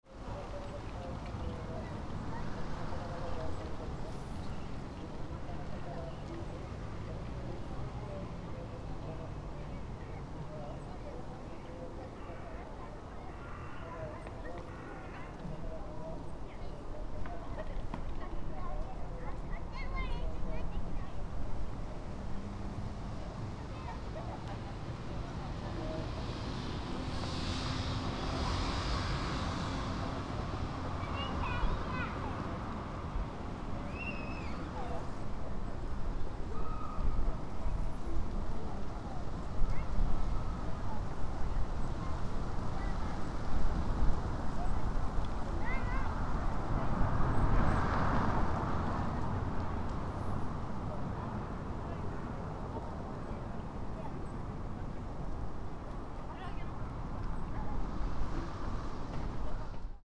On a warmer day in January, several families were playing in this park. ♦ Announcement for the prayer visitor of Gokoku Shrain were heard at the park. ♦ Crows were cawing several times during the recording.